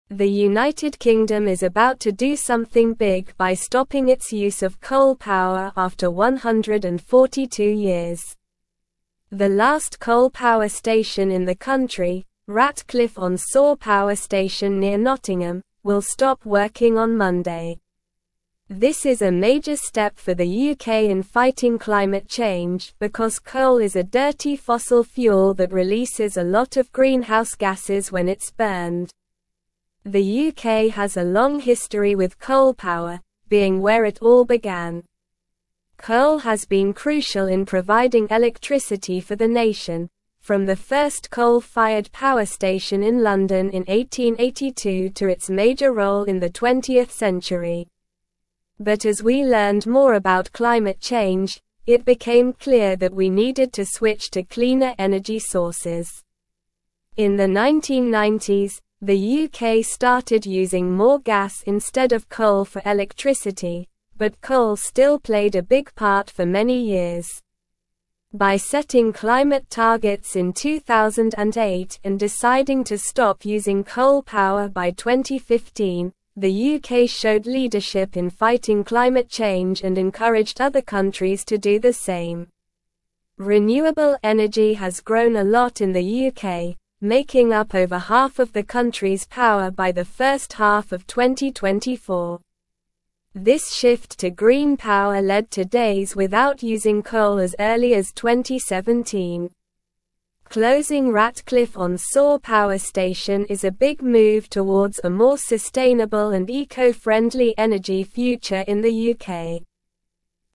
Slow
English-Newsroom-Upper-Intermediate-SLOW-Reading-UK-Ends-142-Year-Coal-Reliance-Shifts-to-Renewables.mp3